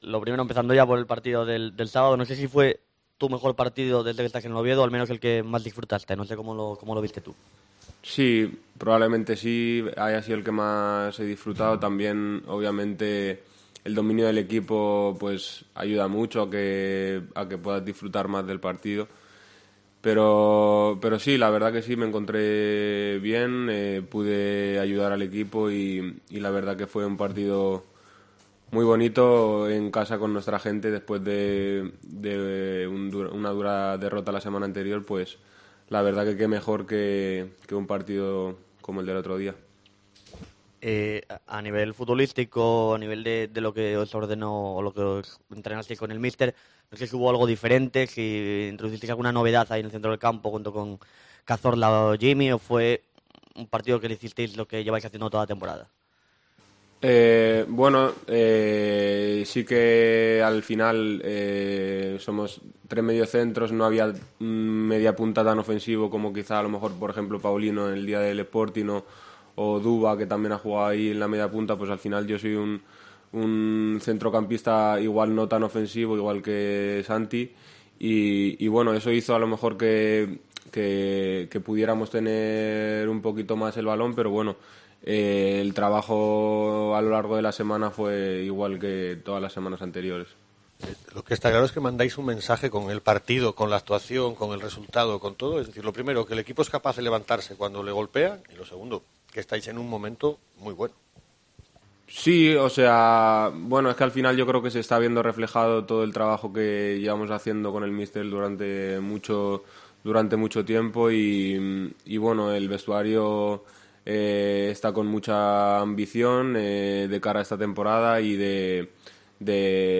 Entrenamiento y rueda de prensa